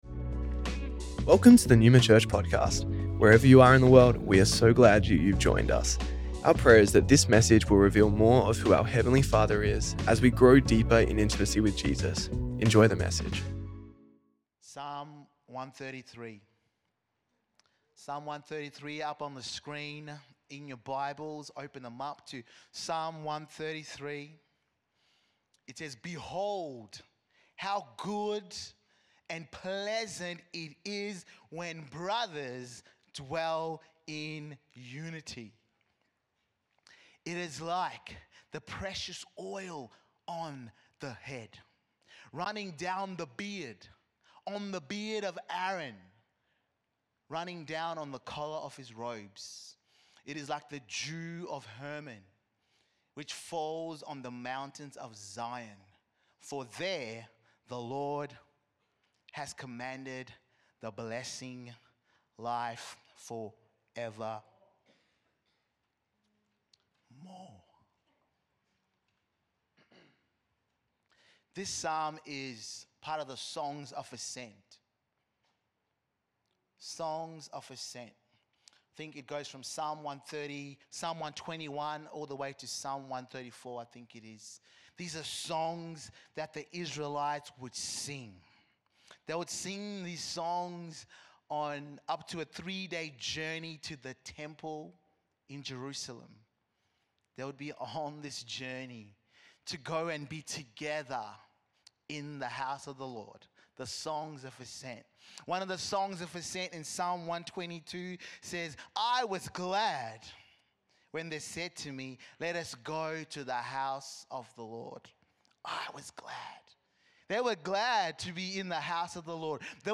Neuma Church Melbourne South Originally recorded at the 10AM Service on Sunday 3rd August 2025